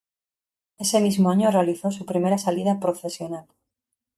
sa‧li‧da
/saˈlida/